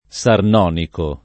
Sarnonico [ S arn 0 niko ]